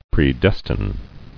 [pre·des·tine]